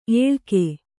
♪ ēḷke